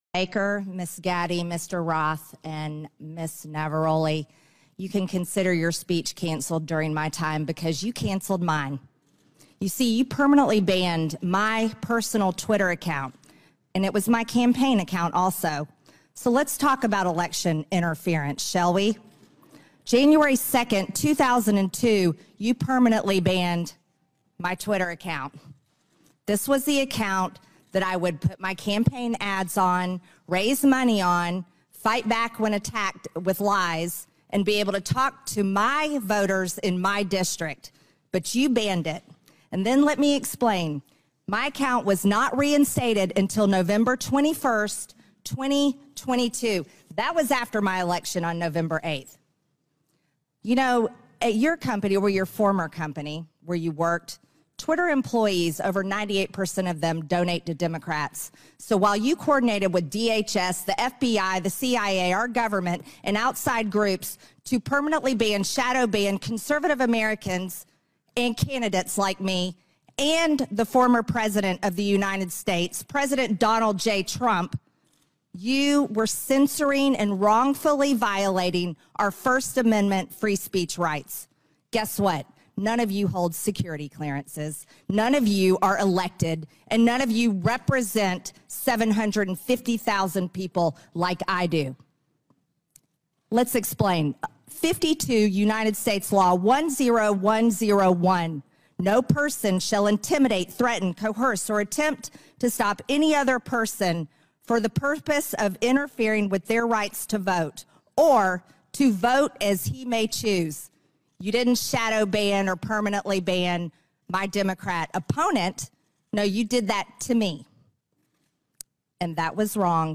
US-Kongressabgeordnete, Marjorie Taylor Greene, hat die harten Bandagen angelegt und lässt in einer 5-minütigen Tirade an die ehemaligen Twitter-Executives nicht aus... wirklich NICHTS!